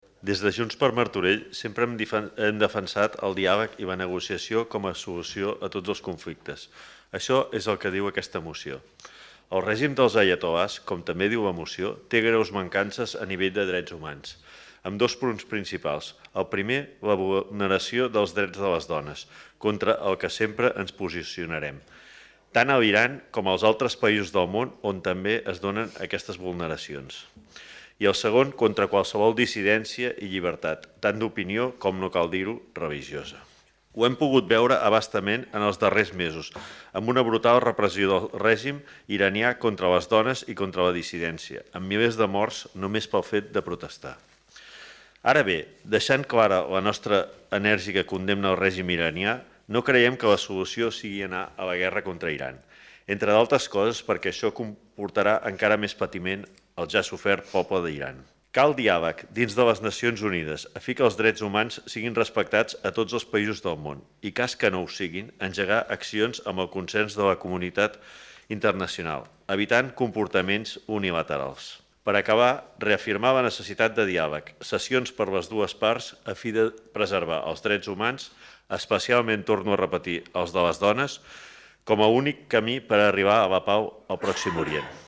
Lluís Sagarra, regidor de Seguretat Ciutadana
Ple-No-a-la-Guerra-04.-Lluis-Sagarra.mp3